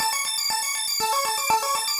SaS_Arp02_120-A.wav